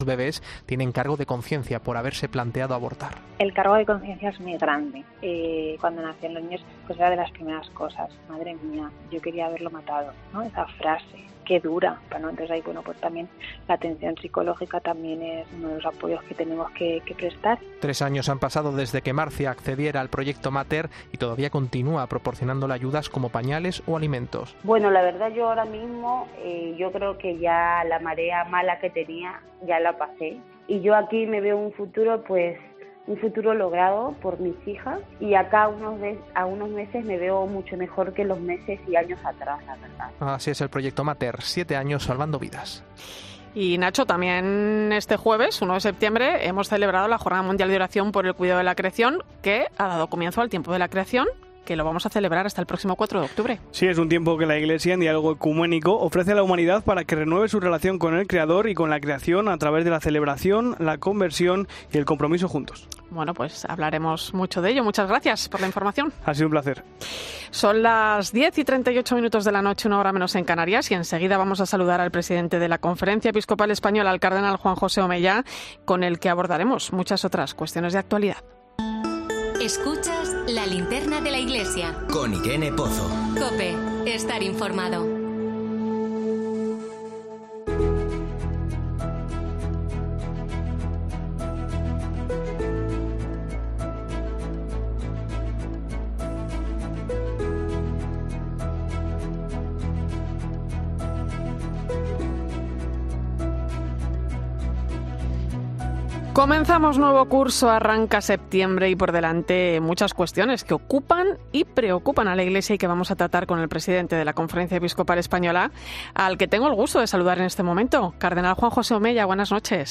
Entrevista al Cardenal Omella en 'La Linterna de la Iglesia' (02/09/2022)